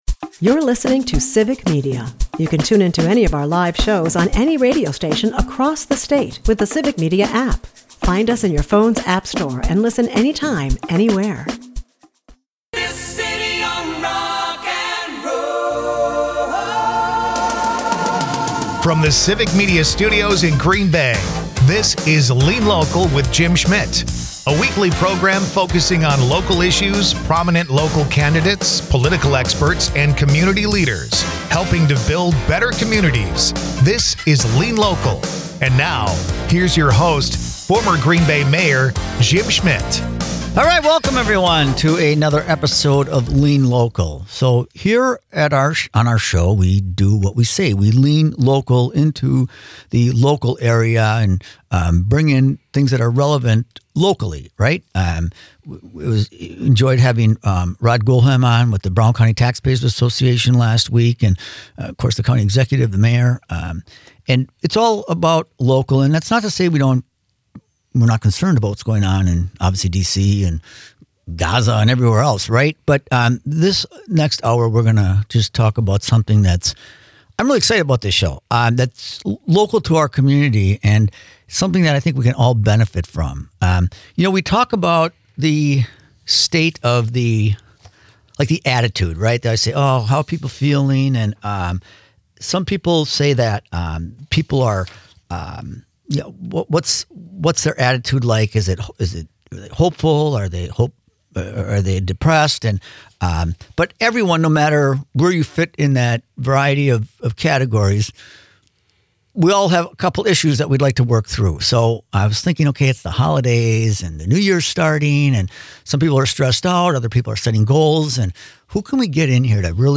a conversation about stress, habits, and what it really takes to make lasting change.